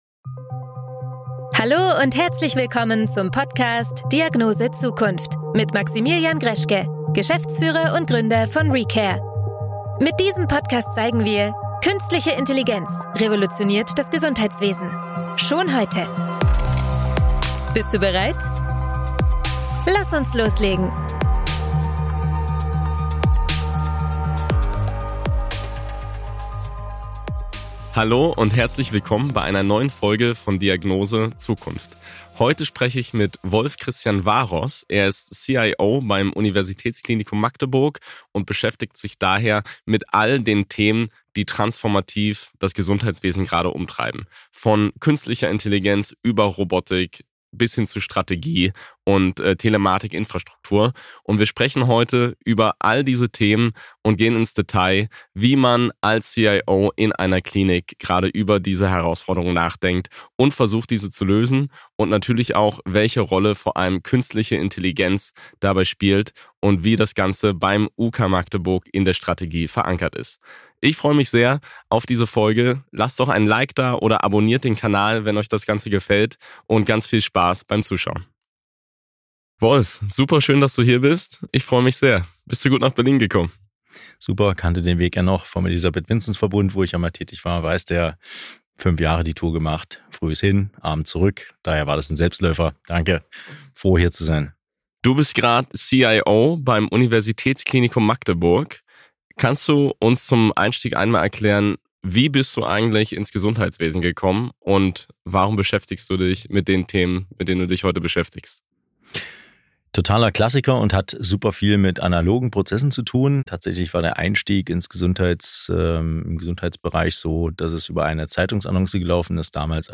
Datensilos, KI und Robotik: Der Weg zur intelligenten Klinik – Interview